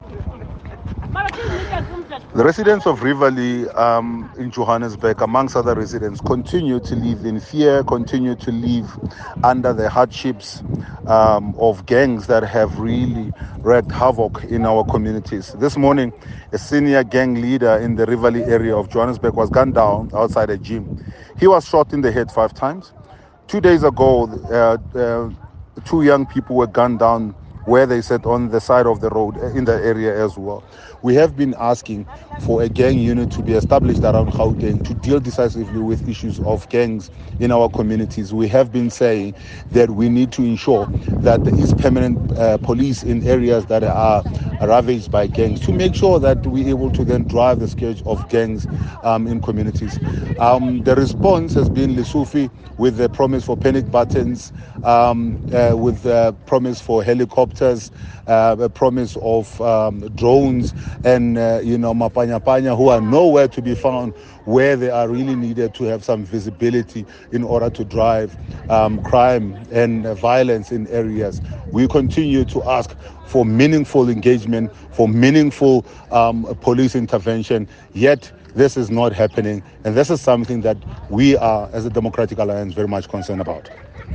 Note to Editors: Please find Eng soundbite by Solly Msimanga MPL